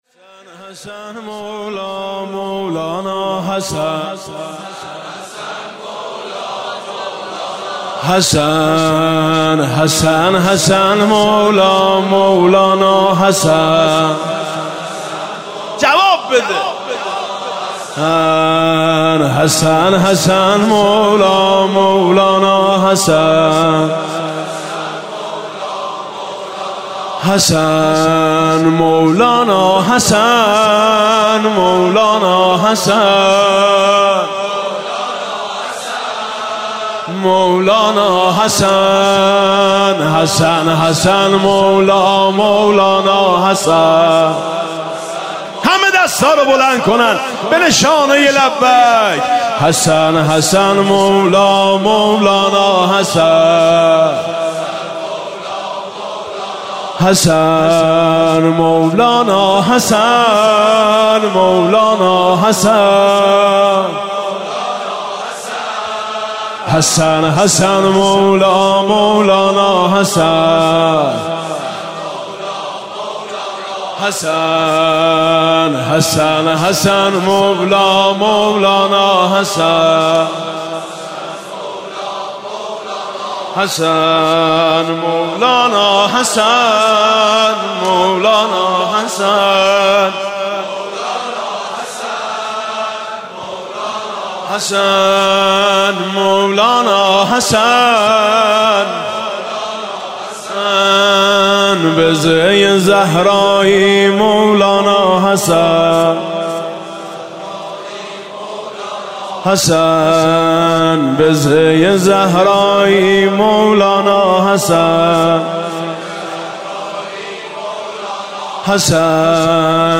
«شب پانزدهم رمضان 1397» سرود: حسن حسن مولا، مولانا حسن